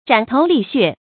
斬頭瀝血 注音： ㄓㄢˇ ㄊㄡˊ ㄌㄧˋ ㄒㄩㄝˋ 讀音讀法： 意思解釋： 形容為匡扶正義而不顧生死。